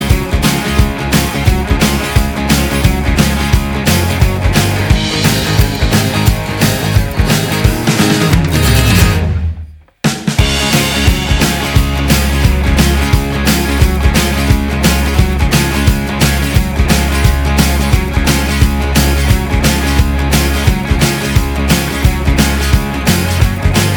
Minus All Guitars Except Rhythm Rock 4:04 Buy £1.50